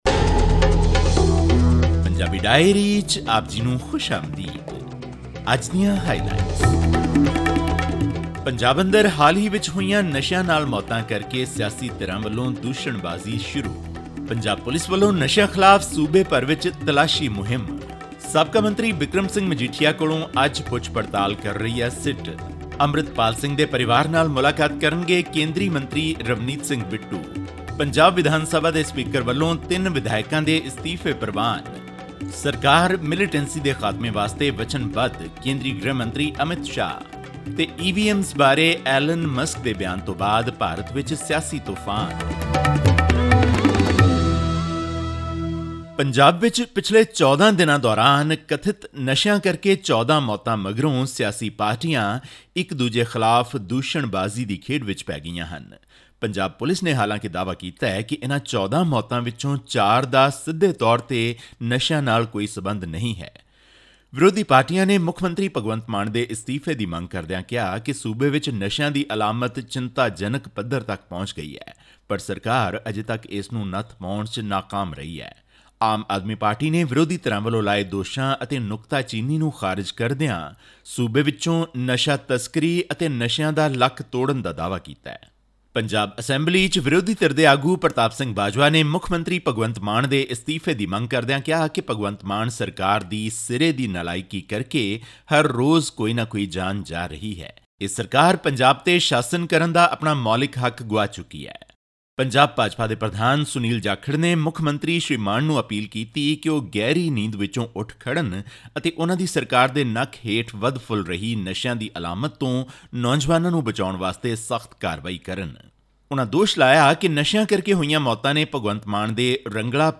ਹੋਰ ਵੇਰਵੇ ਲਈ ਸੁਣੋ ਇਹ ਆਡੀਓ ਰਿਪੋਰਟ...